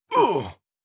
01. Damage Grunt (Male).wav